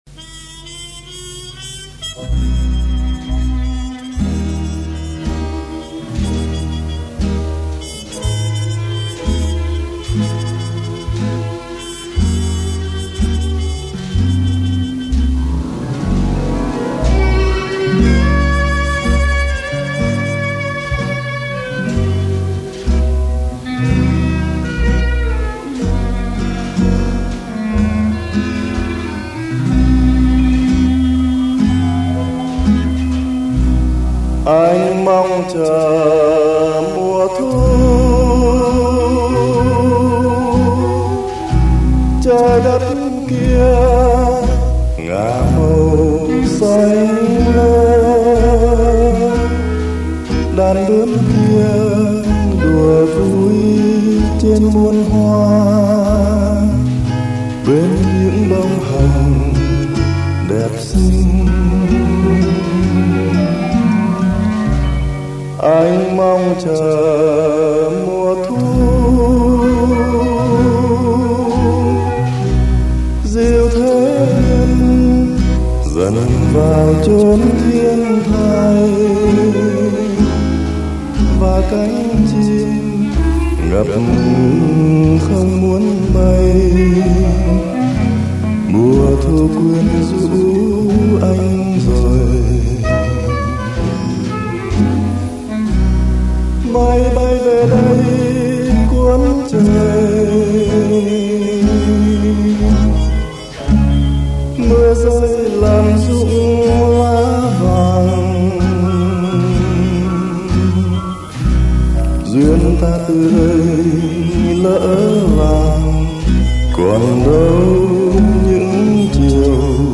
ghi âm trước 1975